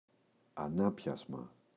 ανάπιασμα, το [a’napçazma]